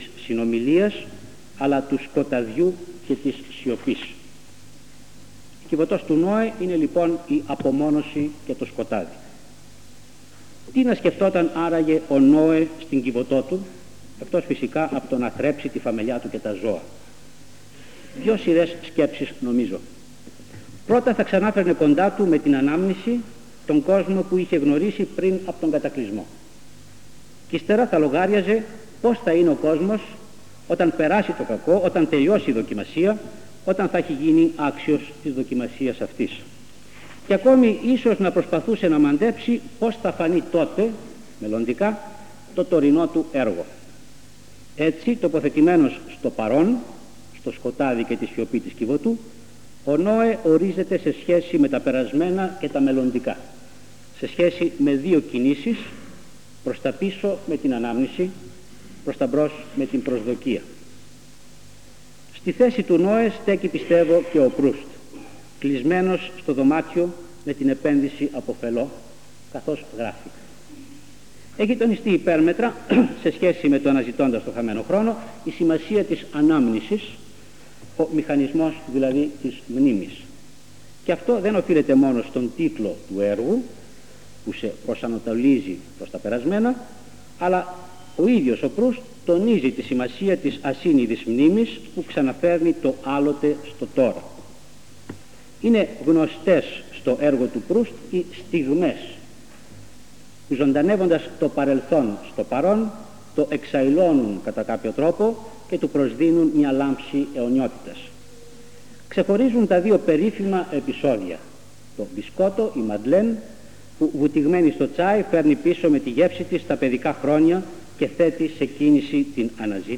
Εξειδίκευση τύπου : Εκδήλωση
Εμφανίζεται στις Ομάδες Τεκμηρίων:Εκδηλώσεις λόγου